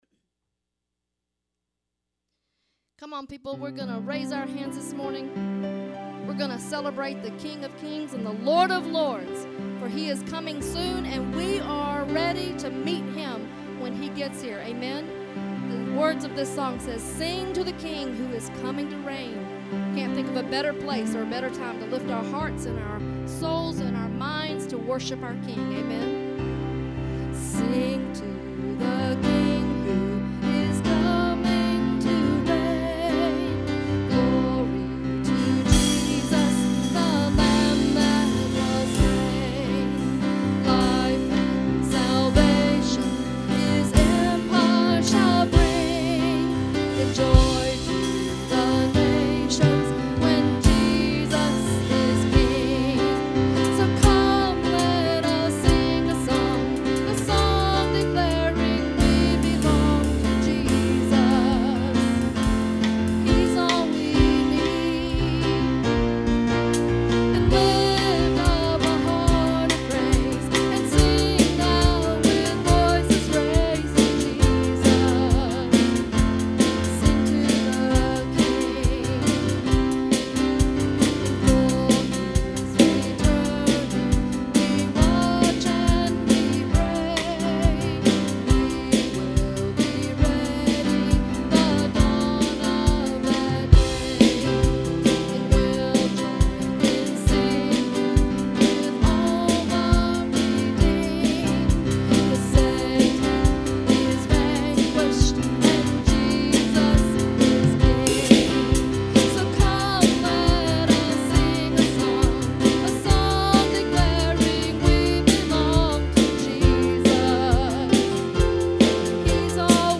A great praise song about the coming of our Lord!